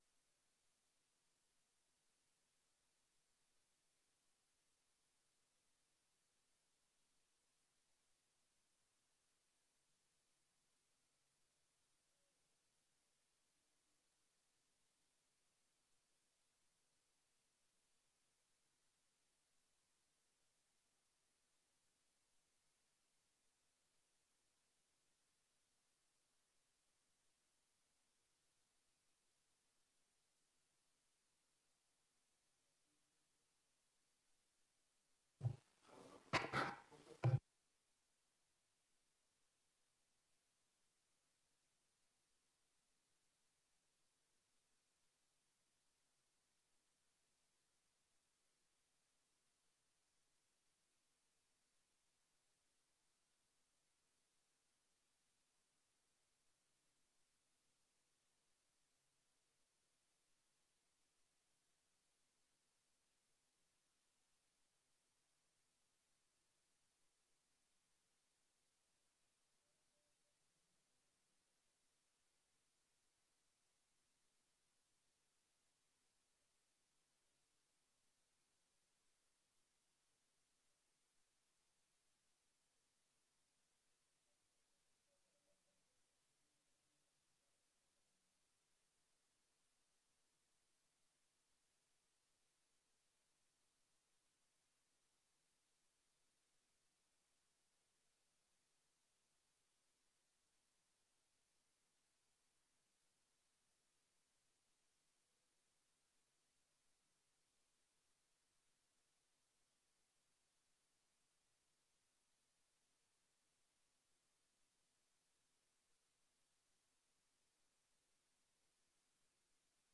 Download de volledige audio van deze vergadering
Locatie: Provinciehuis, zaal Topweer